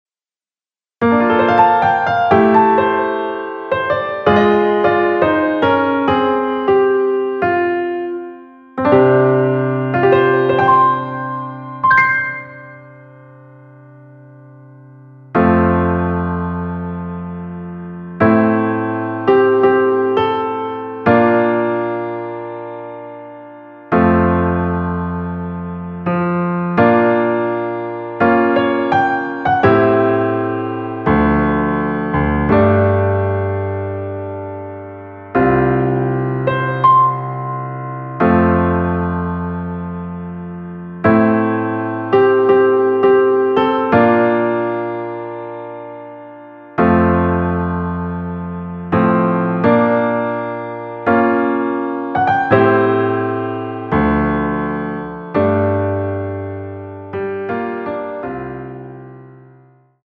앞부분30초, 뒷부분30초씩 편집해서 올려 드리고 있습니다.